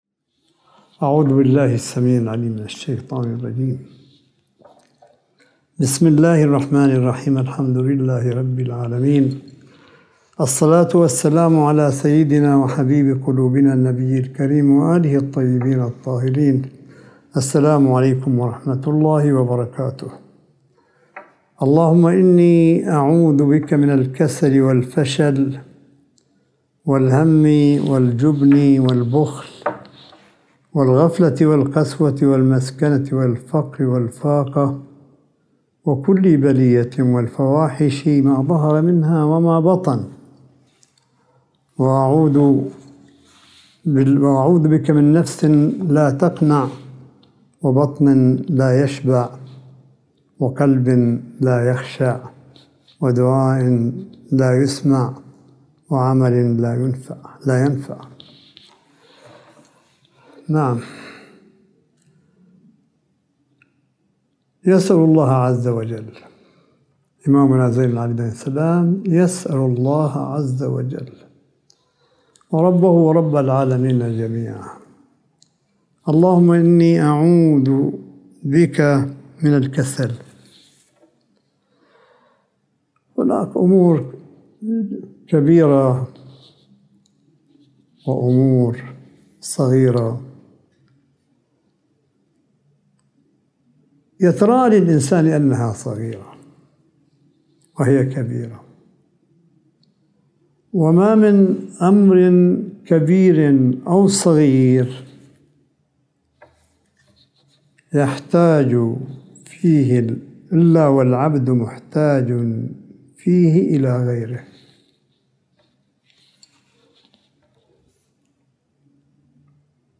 ملف صوتي للحديث الرمضاني (26) لسماحة آية الله الشيخ عيسى أحمد قاسم حفظه الله – 27 شهر رمضان 1442 هـ / 09 مايو 2021م